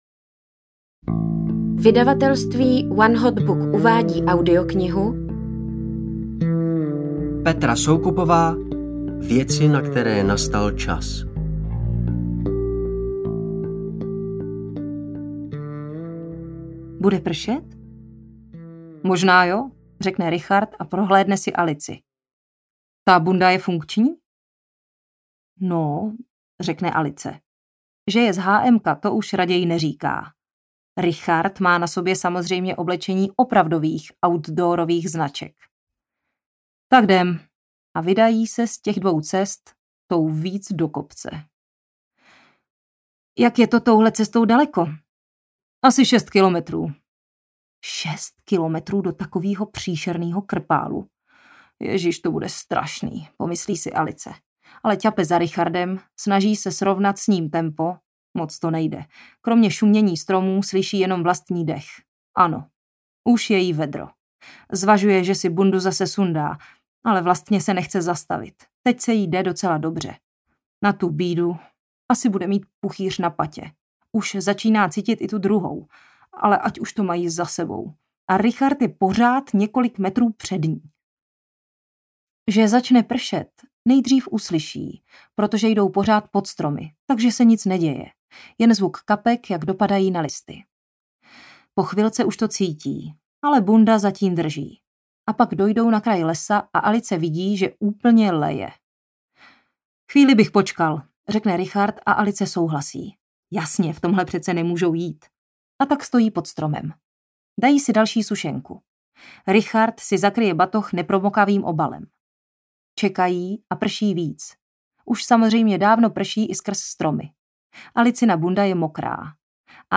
Věci, na které nastal čas audiokniha
Ukázka z knihy